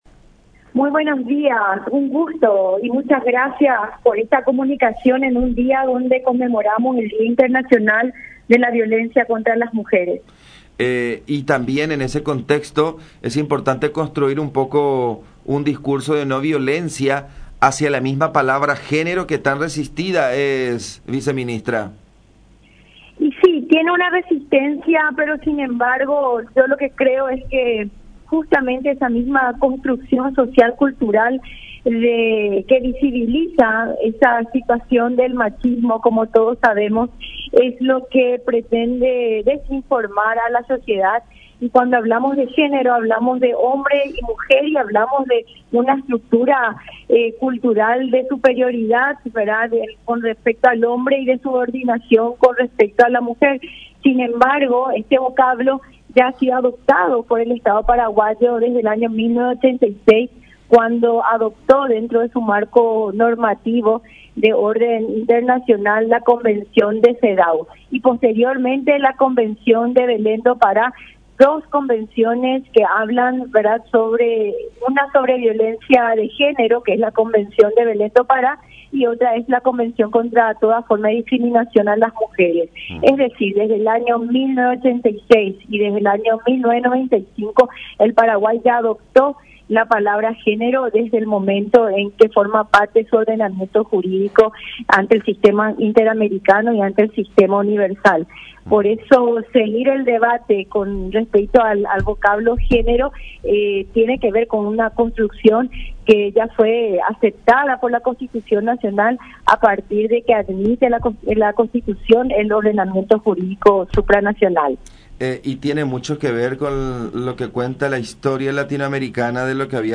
“La palabra género tiene mucha resistencia, pero cuando decimos género, hablamos tanto del hombre como de la mujer, de una cultura de superioridad del hombre y la subordinación de la mujer”, explicó Liliana Zayas, viceministra de la Mujer, en conversación con La Unión, en el marco del Día Internacional contra la Violencia de Género.